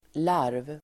Ladda ner uttalet
larv substantiv, larva (plur. larvae)Uttal: [lar:v] Böjningar: larven, larverDefinition: insekt som inte är färdigutveckladSammansättningar: fjärilslarv (caterpillar)